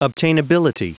Prononciation du mot obtainability en anglais (fichier audio)
Prononciation du mot : obtainability